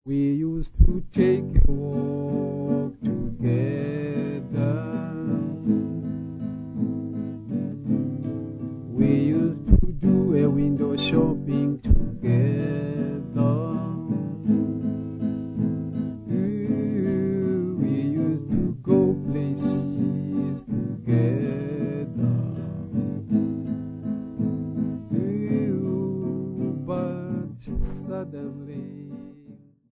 Folk music -- South Africa
Guitar
field recordings
Topical song with guitar accompaniment
96000Hz 24Bit Stereo